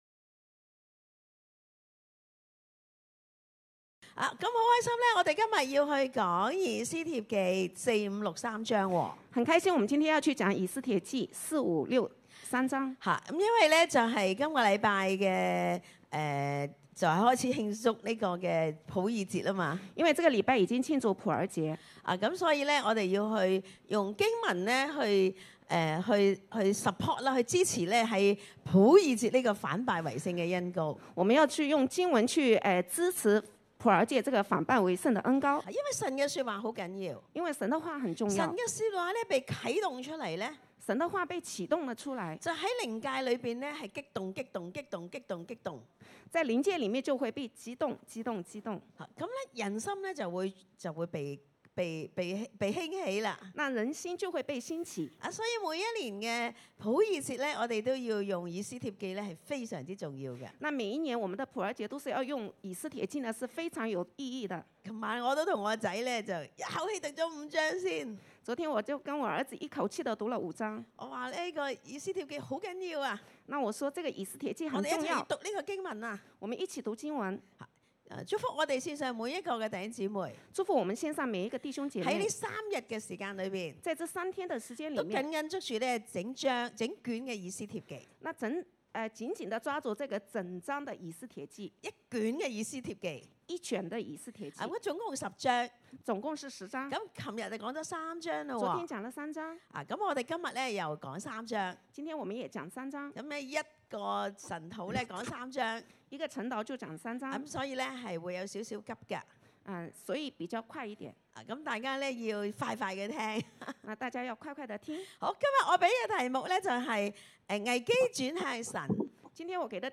A.以斯帖記 第4-6章 【危機轉向神、時機神手中】一.禁食禱告，轉向神(4章)二.剛強智慧，神施恩(5章)三.恰巧時機，神掌權(6章)B.追求1) 讚美感恩神是掌權的神，在我們遇到患難和危機時，垂聽我們的祈禱，出手幫助和拯救我們，進入神的蔭庇，感謝讚美神！2) 分享過去由危機變為轉機的經歷~弟兄姊妹透過 zoom 上分享和感恩~透過Youtube上的聊天室分享3) 為目前遇到的危機禱告*為個人的艱難和香港的疫情禱告，呼求神的名，堅固我們的心，能剛強面對因疫情而衍生的困境。